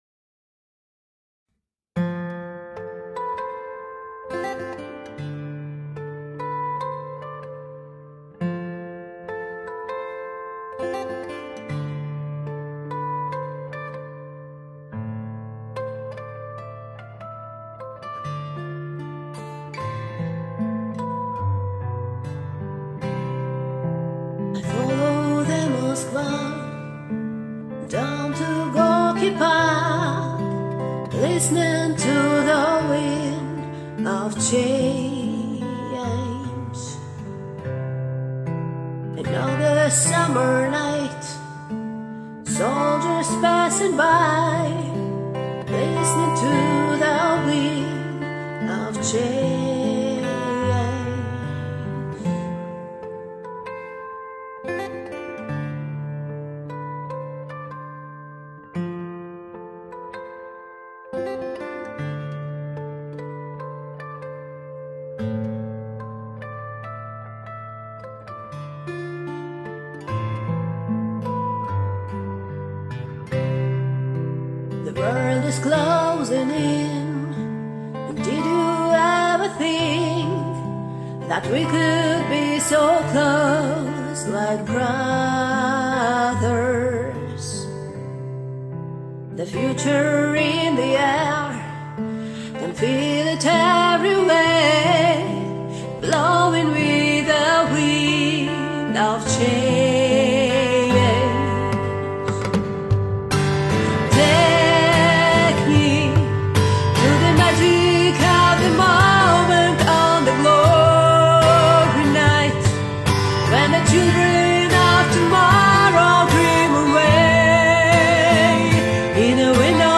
у соперницы как то более гармонично звучит.